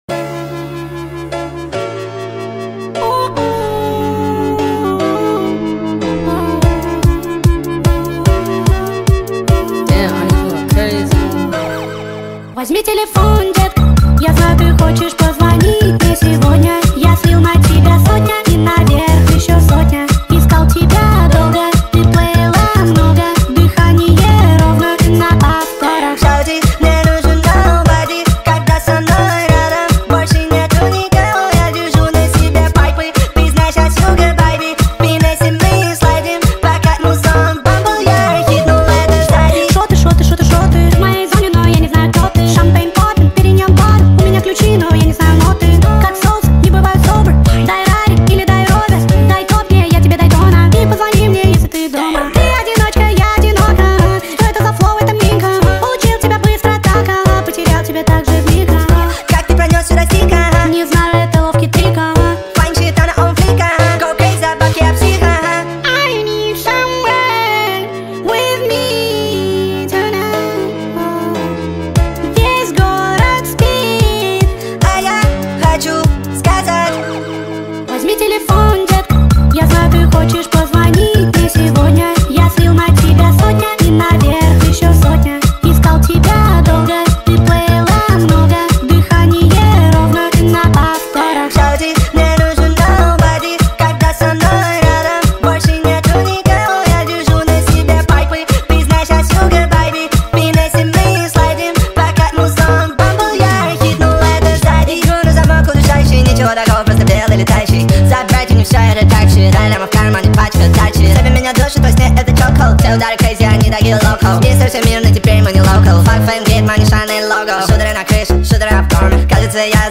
speed up tiktok remix